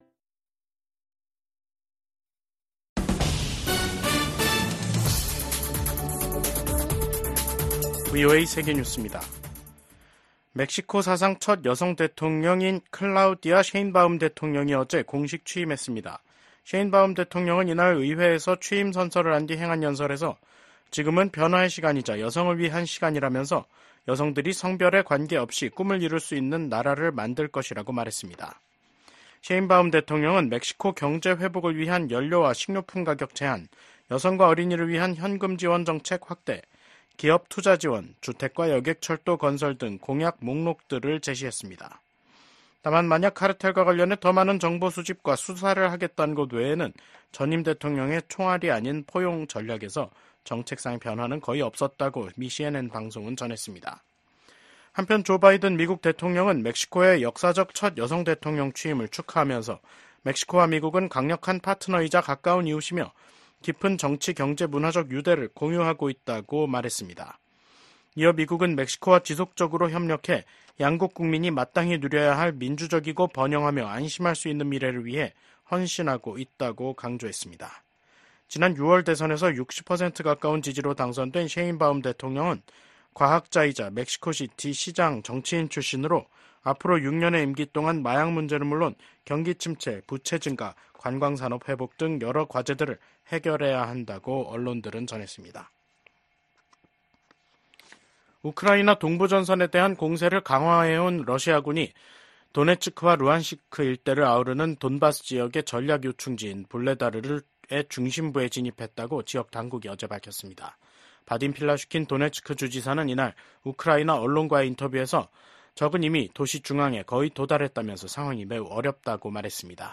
VOA 한국어 간판 뉴스 프로그램 '뉴스 투데이', 2024년 10월 2일 3부 방송입니다. 민주당의 팀 월즈 부통령 후보와 공화당의 J.D. 밴스 후보가 첫 TV 토론에서 안보와 경제, 이민 문제를 두고 첨예한 입장 차를 보였습니다. 토니 블링컨 미국 국무장관은 북한, 중국, 러시아, 이란을 국제 질서를 흔드는 세력으로 규정하며 동맹과 단호히 대응해야 한다고 밝혔습니다.